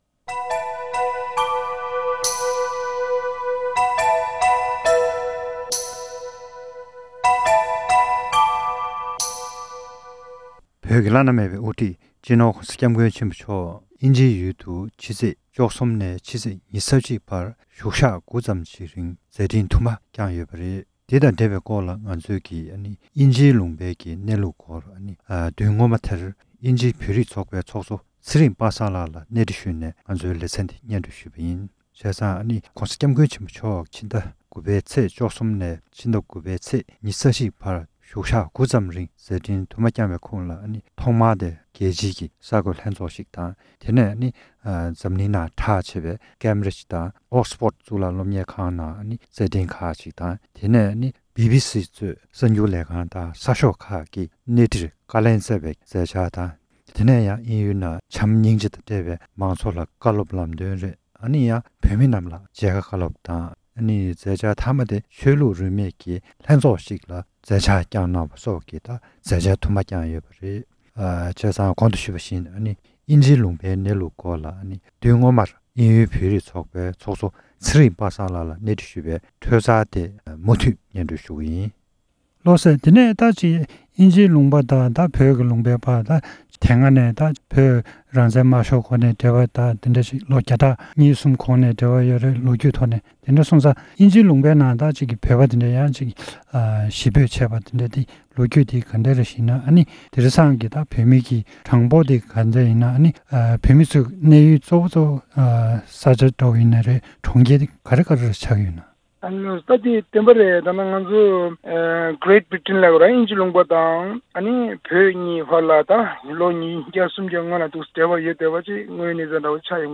གནས་འདྲི་ཞུས་པའི་བདུན་ཕྲག་སྔོན་པའི་འཕྲོས་དེ་གསན་རོགས་གནང་༎